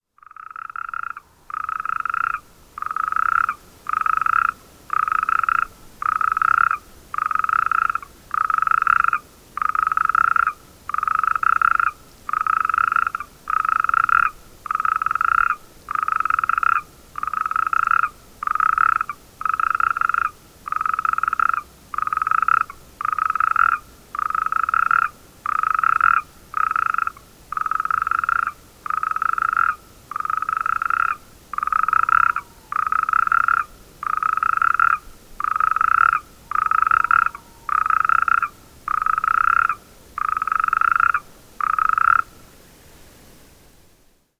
Concert de crapauds calamites à Tresserre